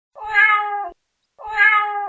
Cat Soundboard: Play Instant Sound Effect Button
The Cat sound button is a popular audio clip perfect for your meme soundboard, content creation, and entertainment.